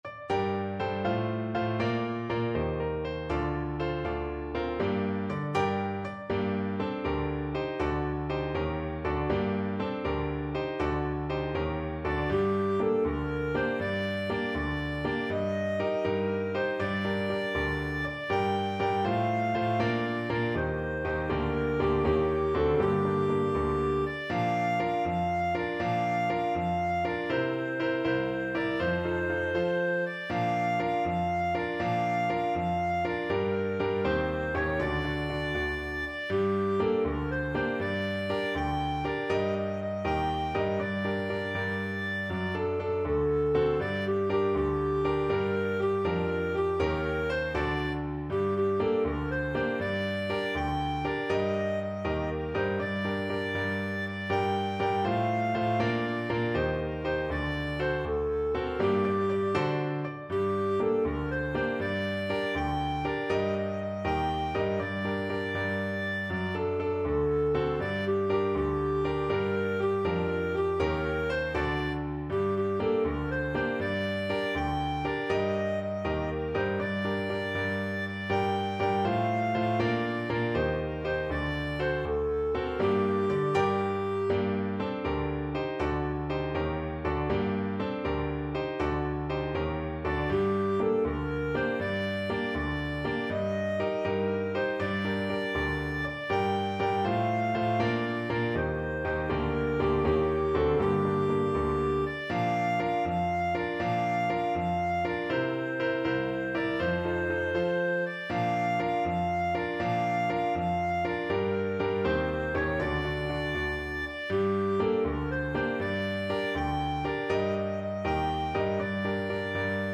6/8 (View more 6/8 Music)
Brightly, but not too fast
Clarinet pieces in G minor